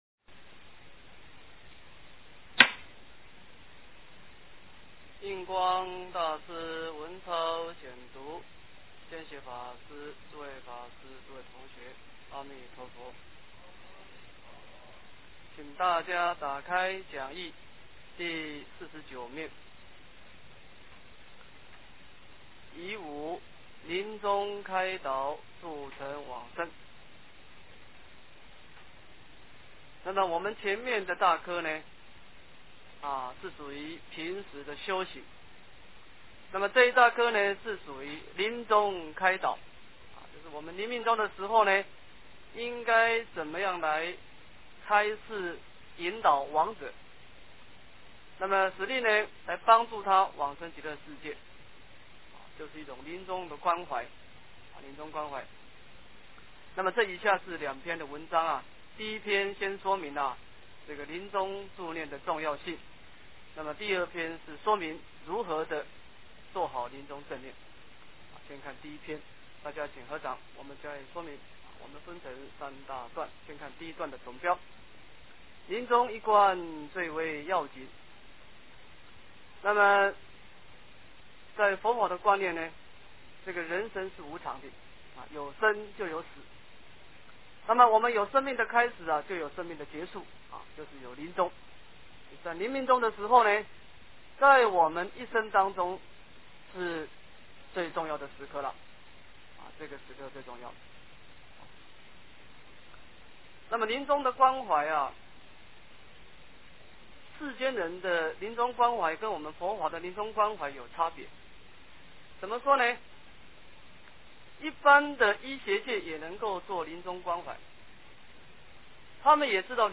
印光法师文钞23 - 诵经 - 云佛论坛